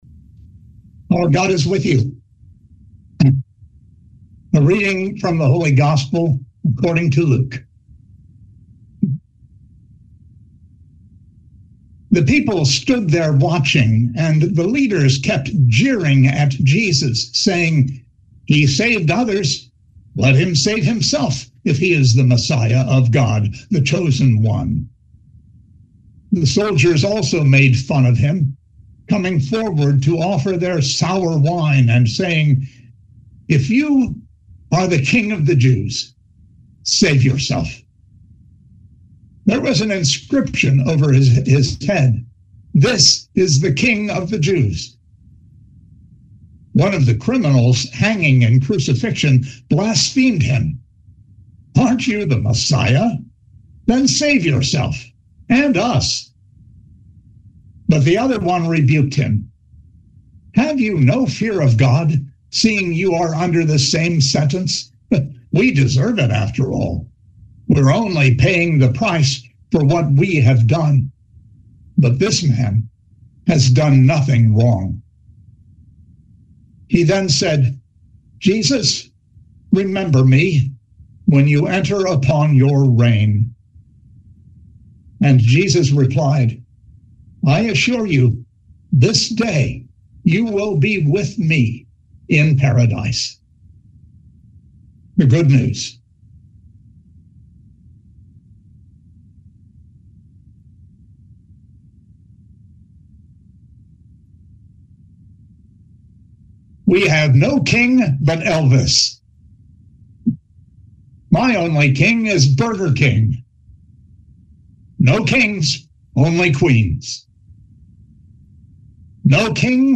Living Beatitudes Community Homilies: Of Christ and Kings